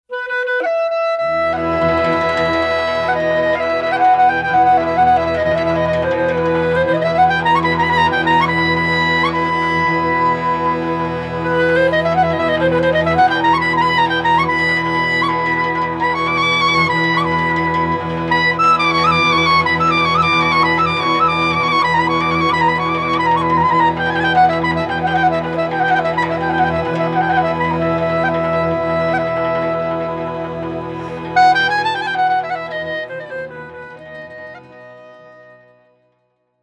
Violin
C Clarinet
Accordions, Tsimbl
Bass Cello
Genres: Klezmer, Polish Folk, Folk.